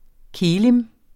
Udtale [ ˈkeːlim ]